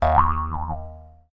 trampoline-cartoon-02.ogg